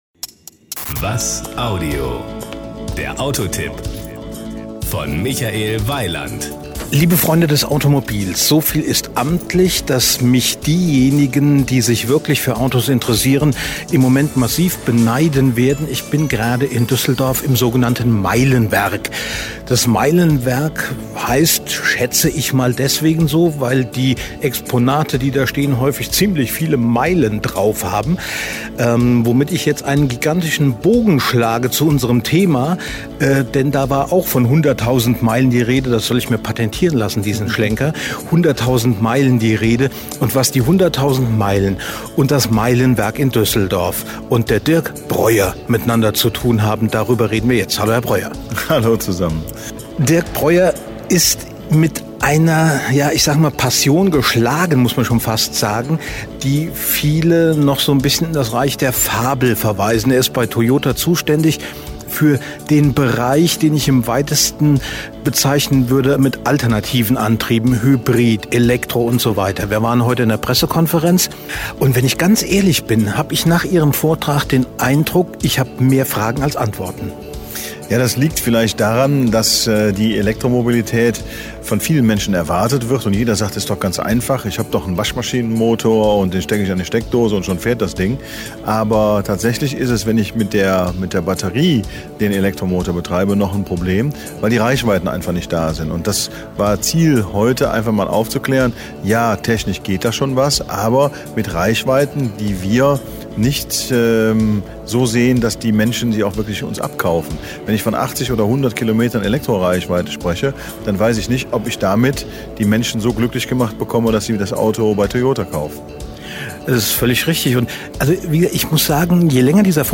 Sie sind hier: Start » Interviews » Interviews 2009